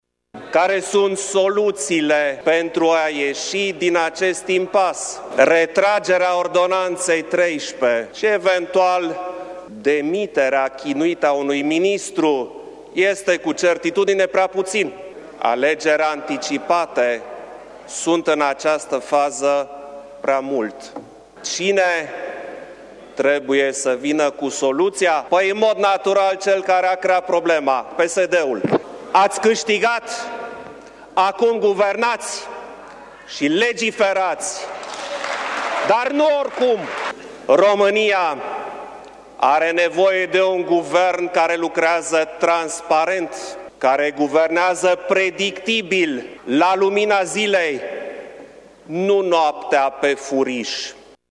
Așa și-a început șeful statului discursul în plenul Parlamentului.